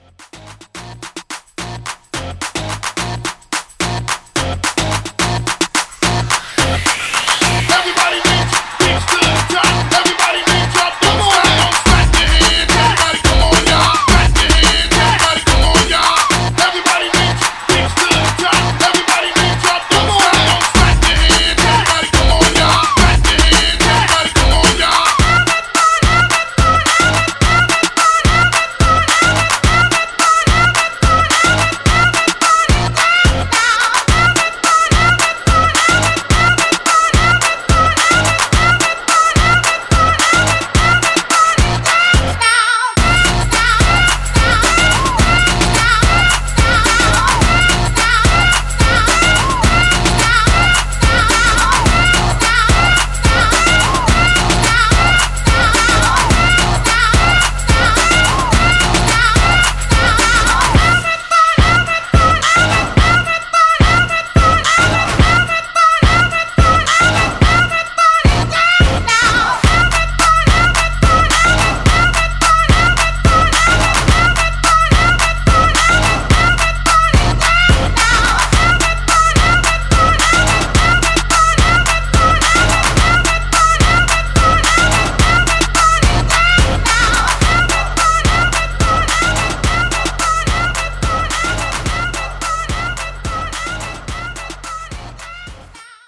BOOTLEG , DANCE , TWERK